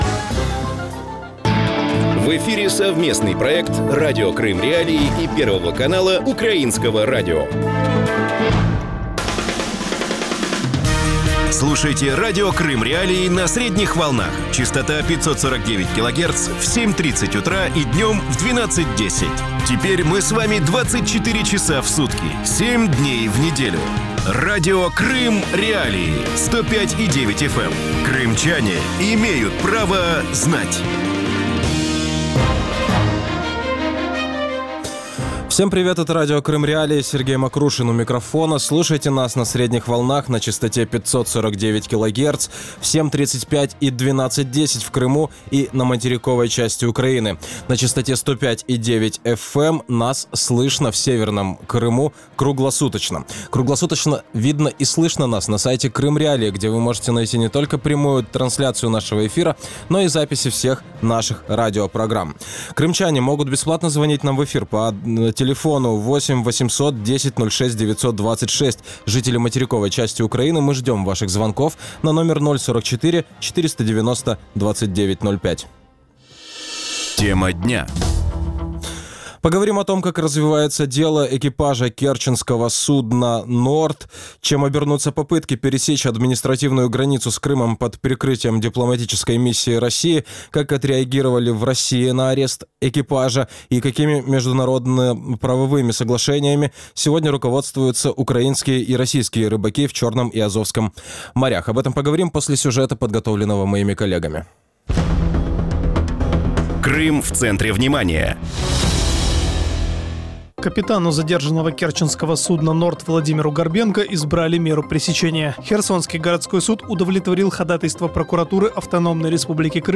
политический эксперт.